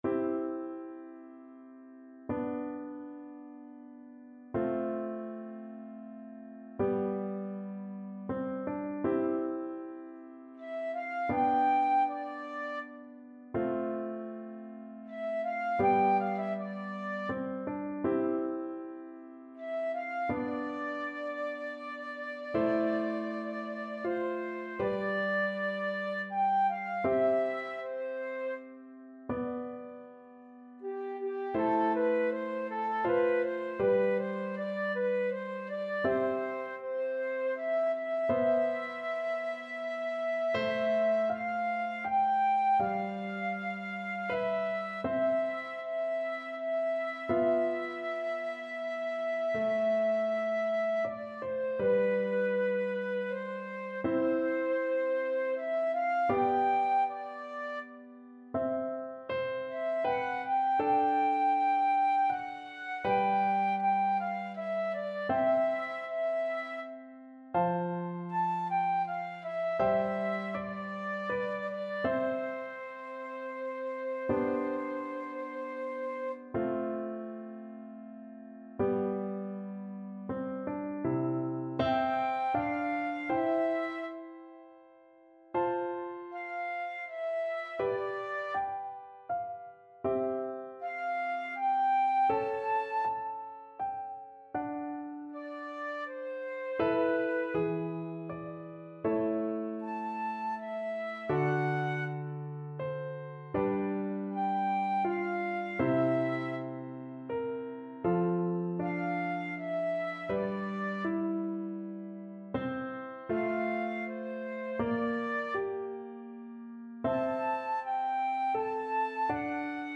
3/4 (View more 3/4 Music)
Andante
Classical (View more Classical Flute Duet Music)